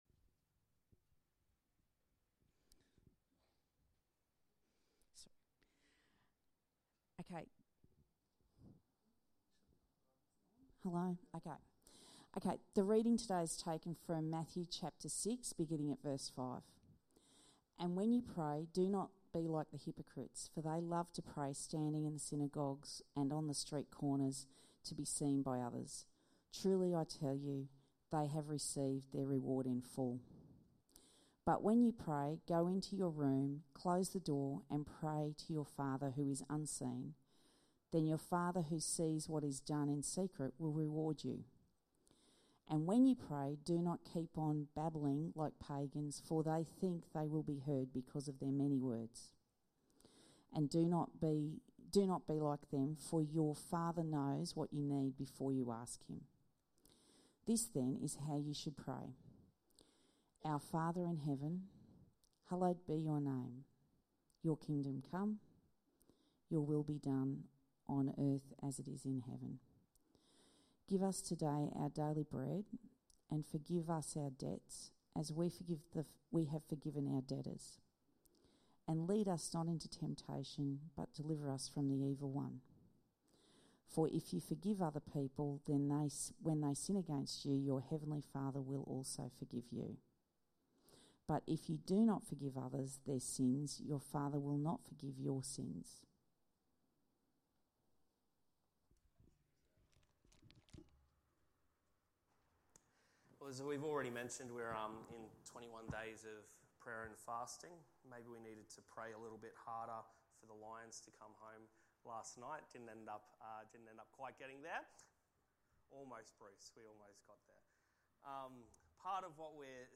Sermons | Church At The Gabba